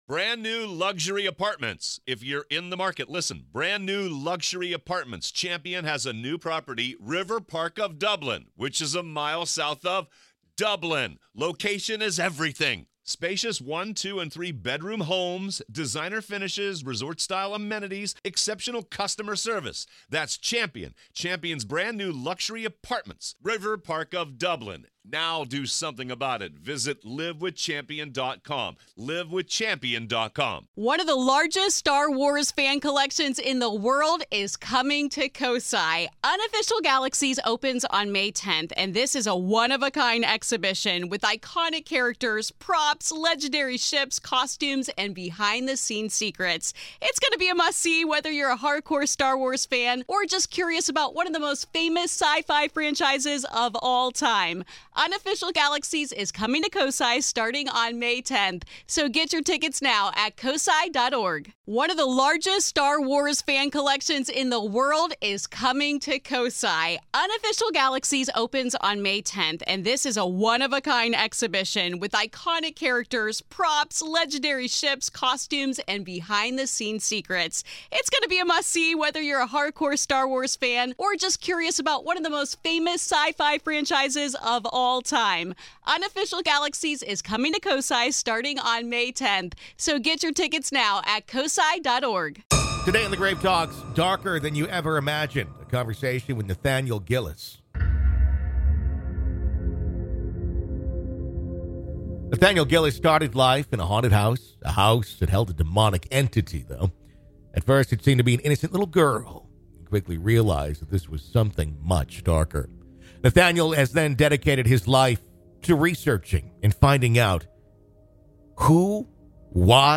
Growing Up Haunted | A Conversation